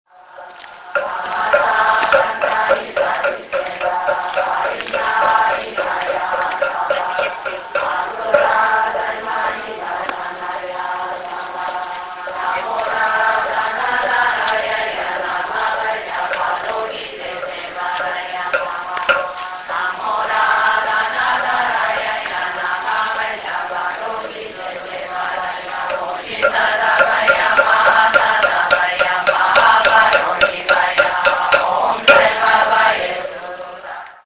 chanting.mp3